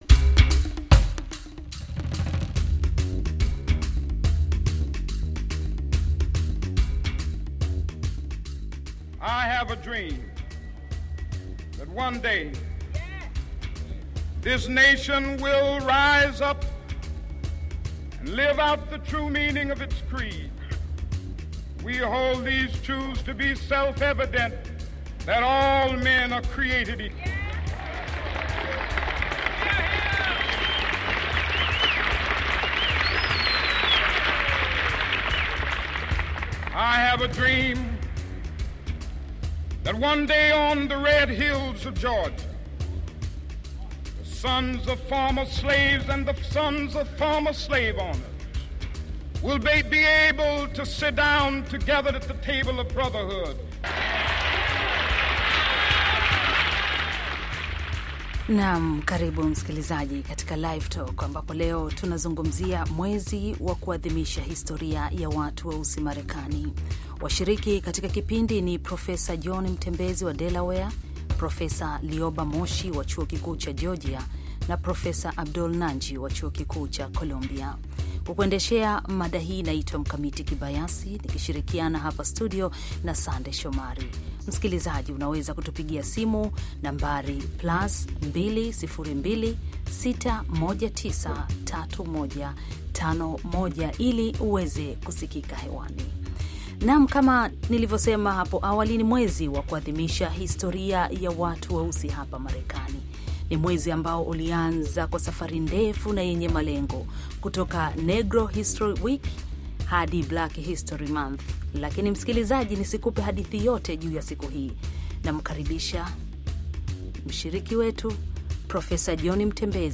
Mjadala wa mwezi wa sherehe za watu weusi Marekani